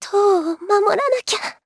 Dosarta-Vox_Dead_jp.wav